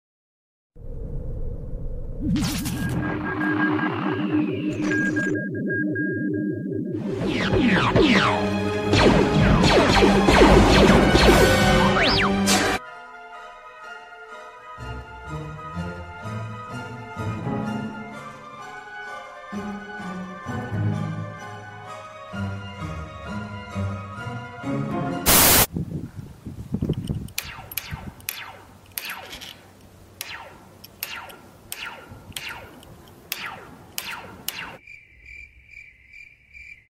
Secrets of Star Wars: Blaster sound effects free download